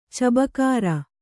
♪ cabakāra